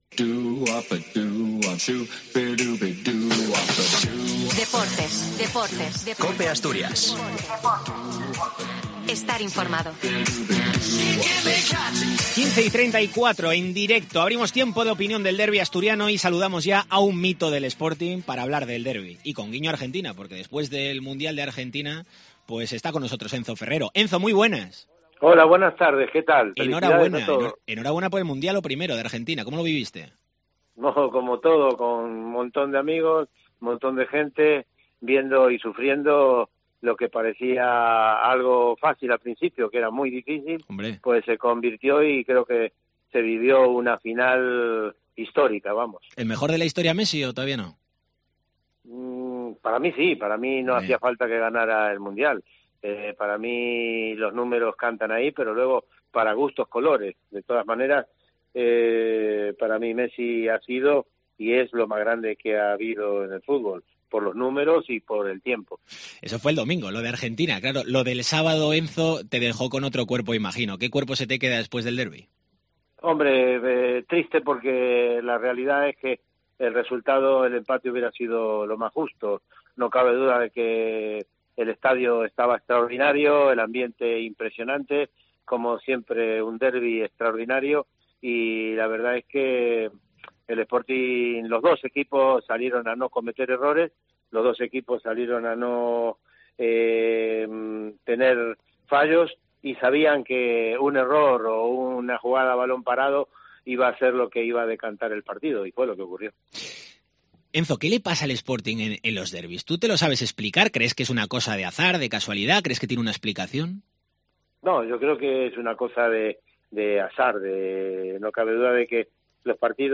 Después de un fin de semana muy intenso para él a nivel futbolístico, con el derbi asturiano y la final del Mundial de Argentina, Enzo Ferrero atiende la llamada de la Cadena COPE para opinar sobre la derrota del Sporting y también el triunfo de la albiceleste.